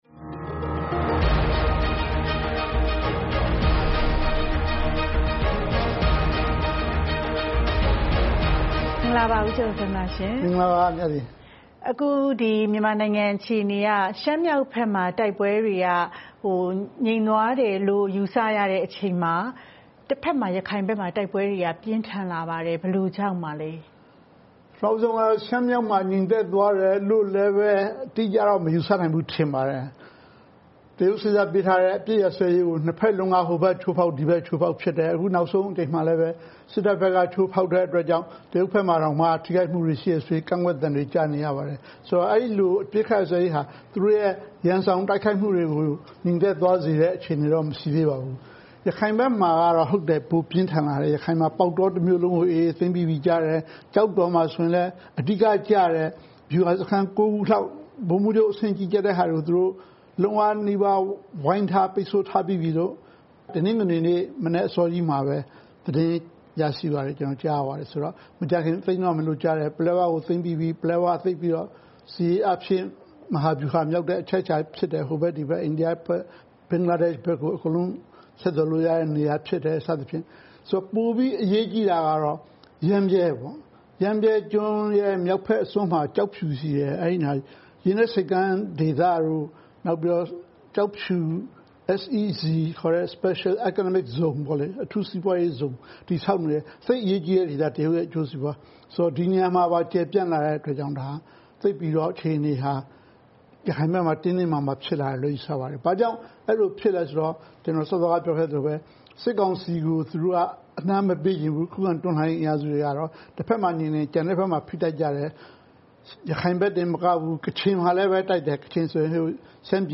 ဆွေးနွေးမေးမြန်းထားပါတယ်။